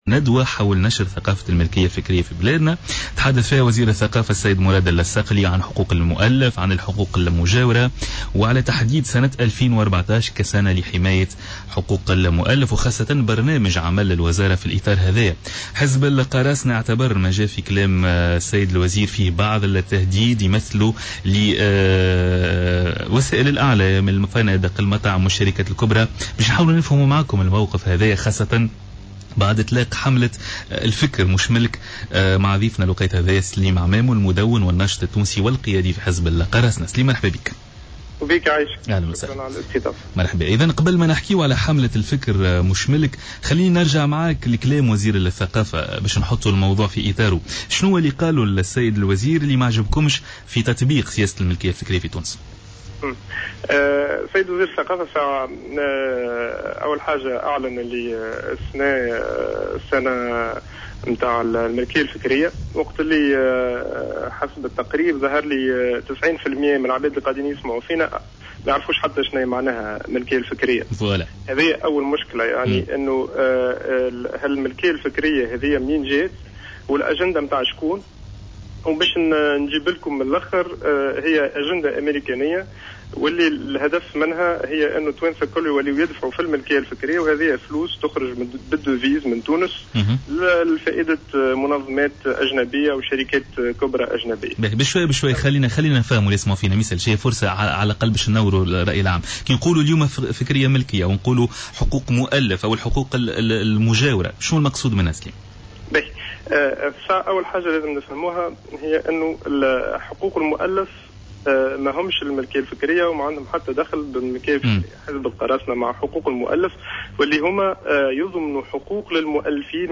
علّق سليم عمامو المدون ورئيس حزب القراصنة،اليوم،الخميس في مداخلو له في برنامج "بوليتيكا" على "جوهرة أف أم" على تصريحات وزير الثقافة والتي قال فيها أنه سيقوم بتتبعات عدليّة ضد الشركات التونسية لاستخلاص معاليم الملكية الفكرية باسم منخرطين أجانب.